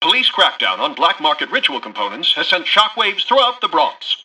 Newscaster_headline_30.mp3